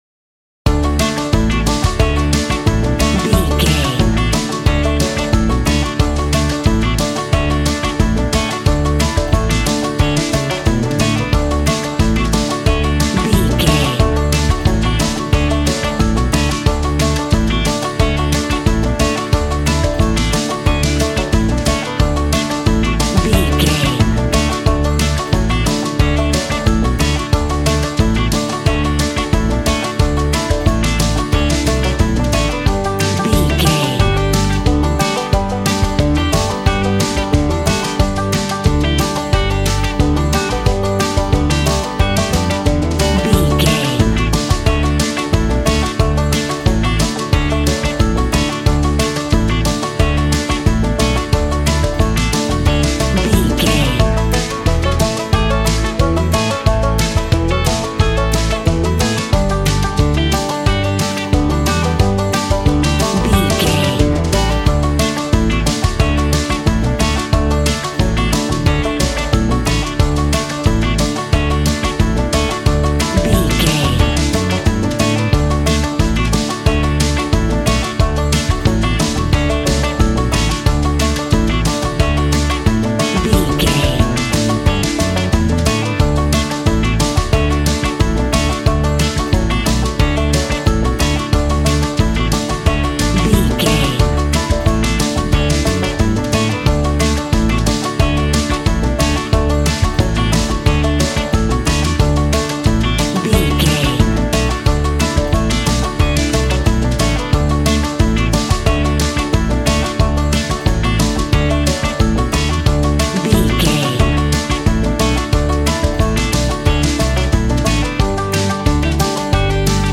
Country music with a rock and roll / Jive twist.
Ionian/Major
Fast
fun
bouncy
double bass
drums
acoustic guitar